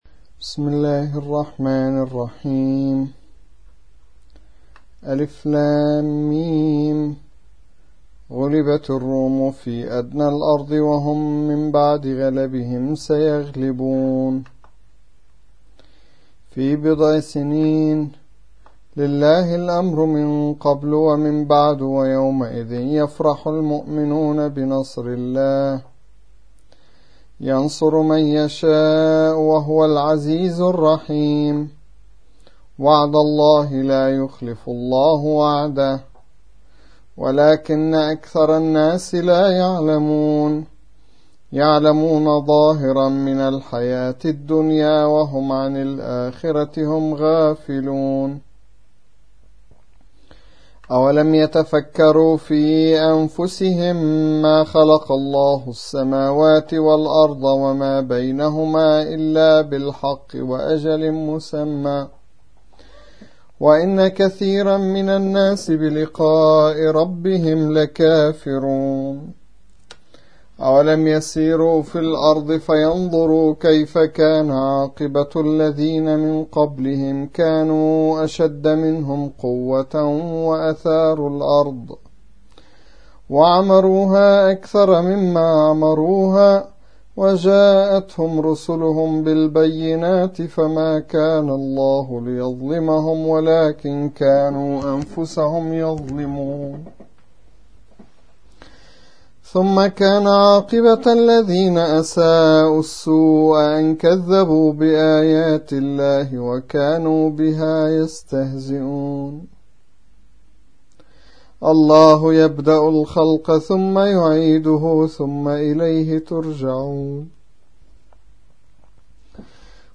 30. سورة الروم / القارئ